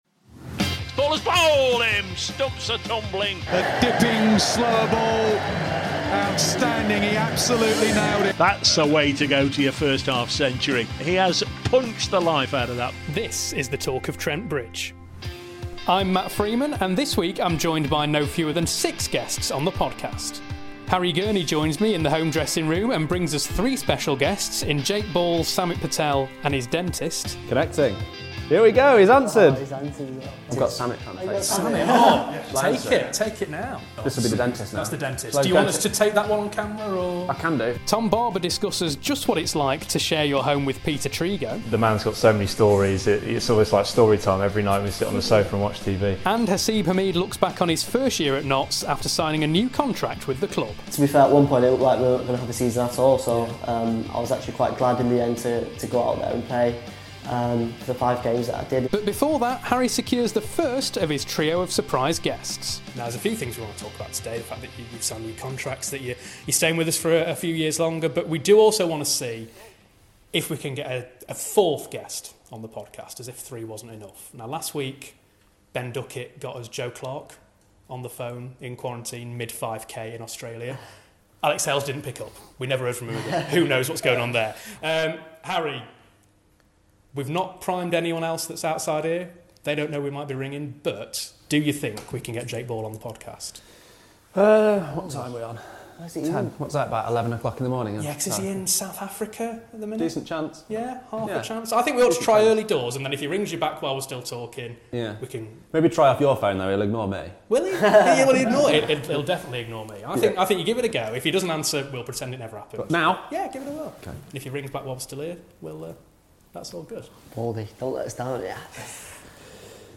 Three studio guests, two overseas Outlaws, and one dentist - episode two of the Talk of Trent Bridge podcast is by no means light on surprises.